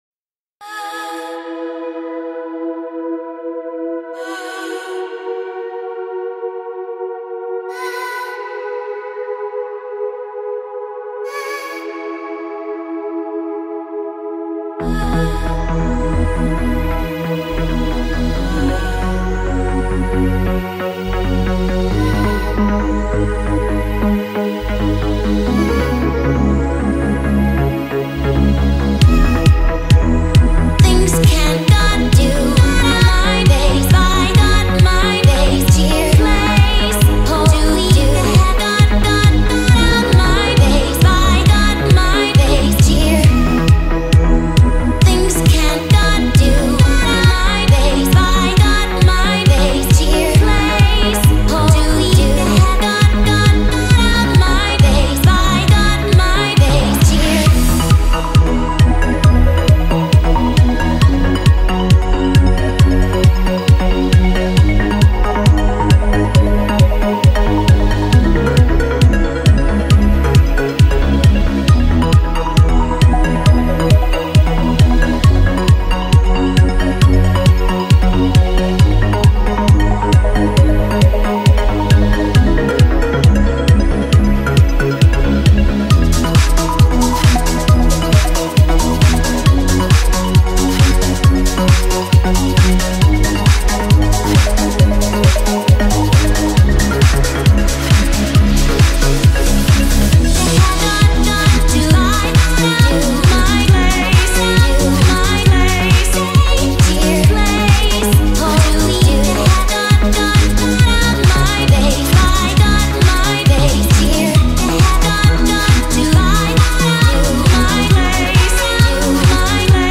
BPM: 135